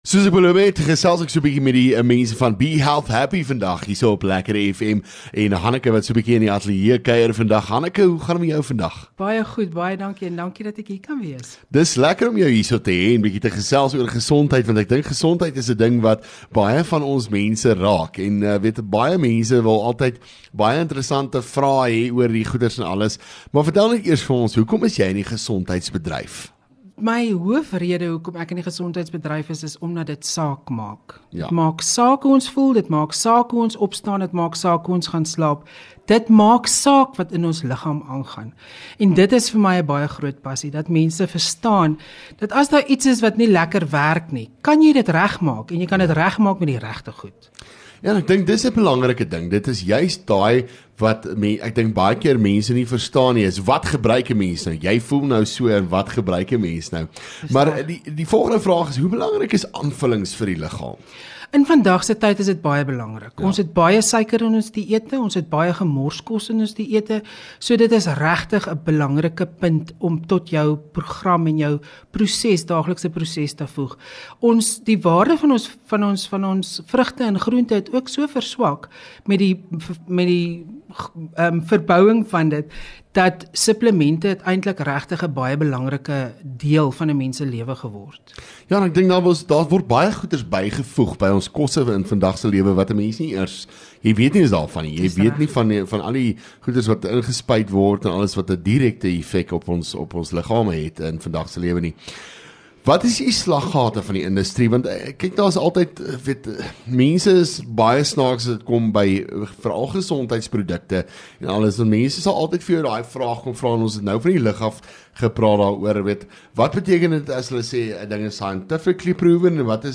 LEKKER FM | Onderhoude 9 Jan Be Health Happy